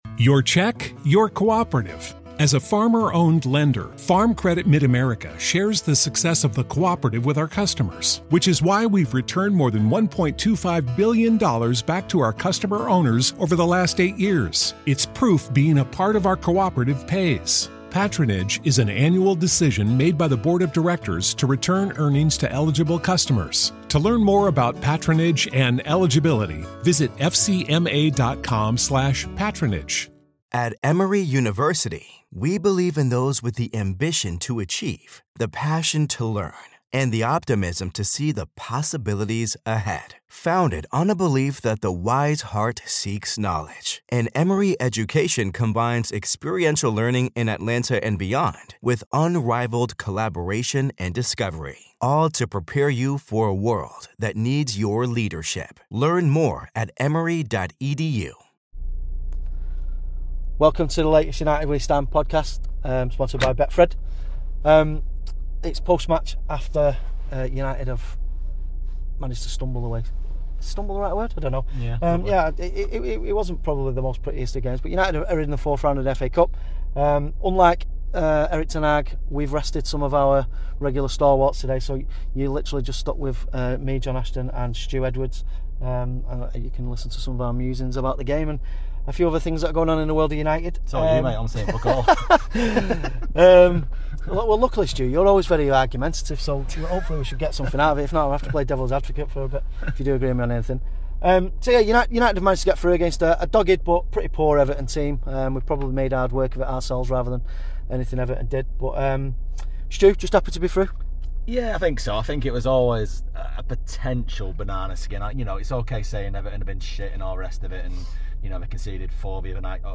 Reds (and an Evertonian) from Old Trafford.